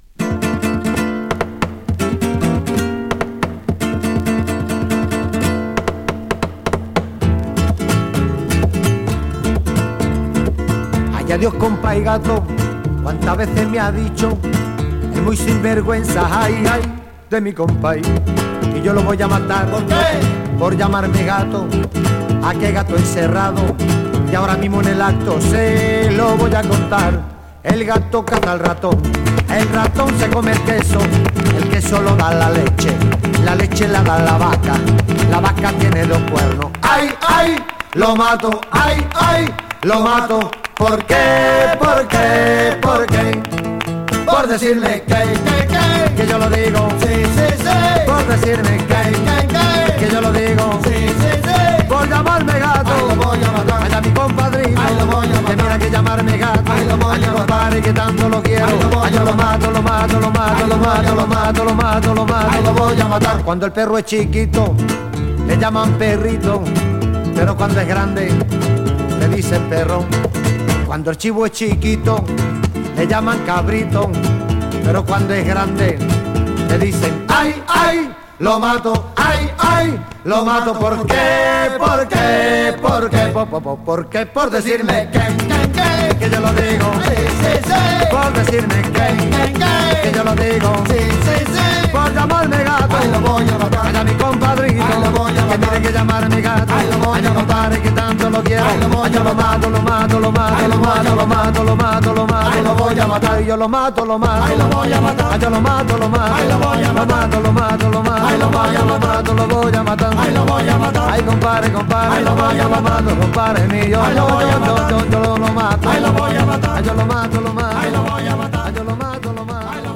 Published June 2, 2011 Garage/Rock Comments